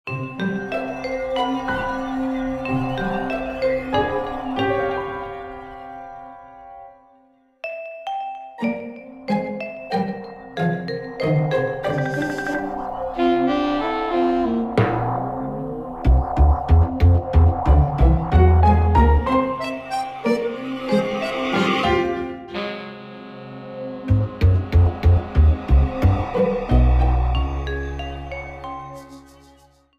Shortened, applied fade-out, and converted to oga